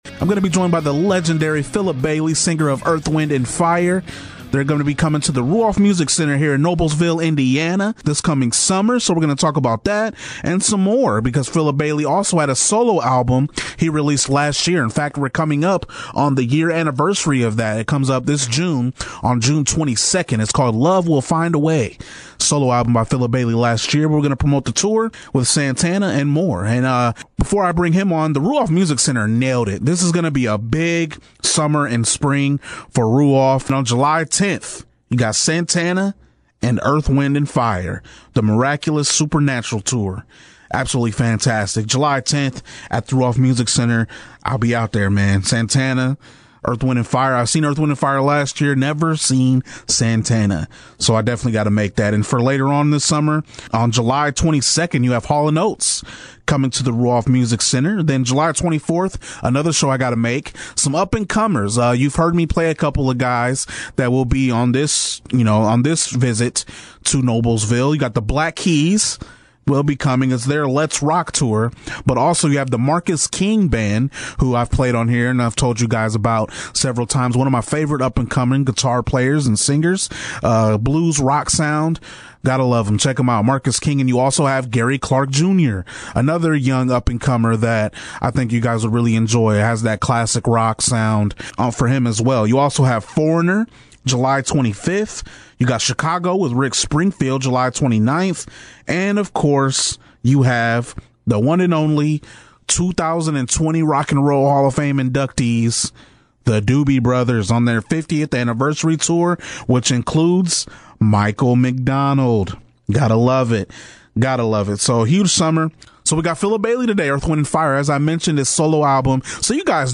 Interview with Philip Bailey from Earth, Wind & Fire